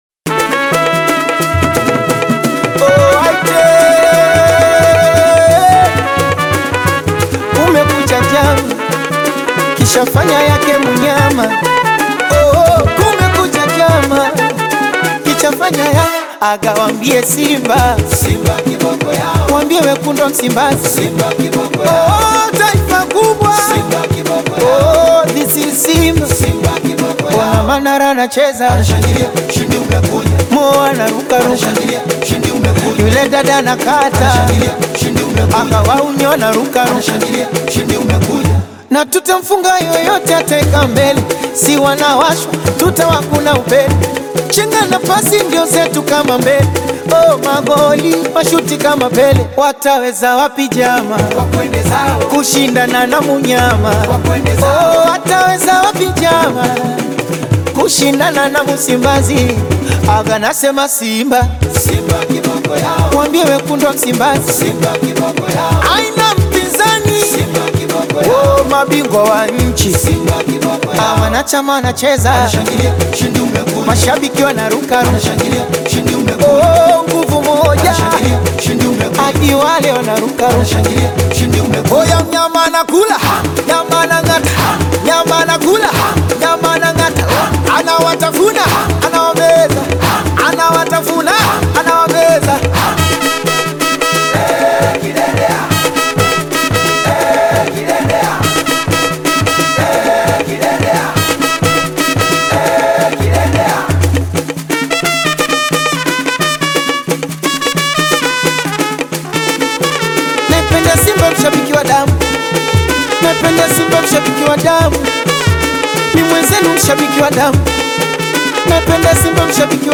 he goes with a more traditional approach on this one.